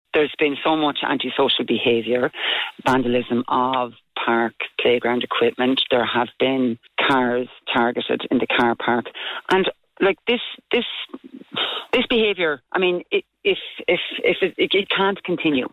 Speaking on Kildare Today, she said the signs would feature a QR code directing people to the relevant places to lodge a complaint.